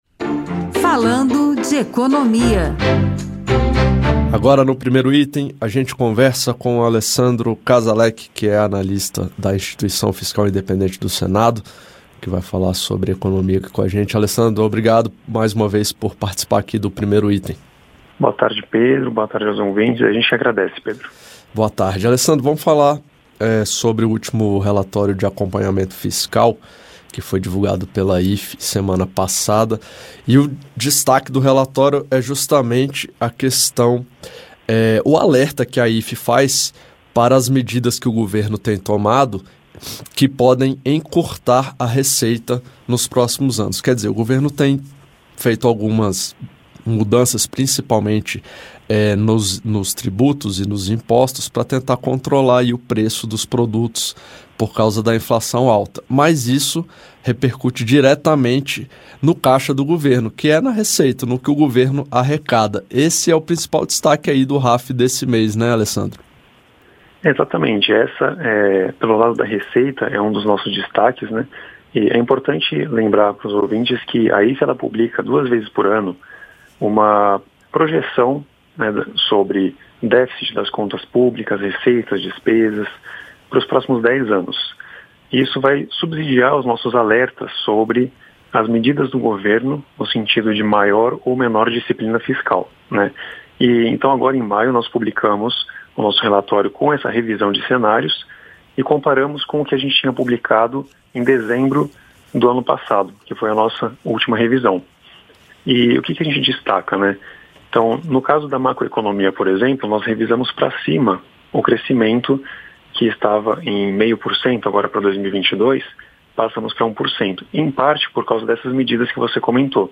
Medidas como a redução do IPI e a desoneração tributária dos combustíveis podem ter forte impacto sobre a arrecadação federal e sobre as contas de estados e municípios nos próximos anos. A Instituição Fiscal Independente (IFI) do Senado tem estudo sobre o efeito estimado de R$ 31,4 bilhões nas receitas públicas. Acompanhe a entrevista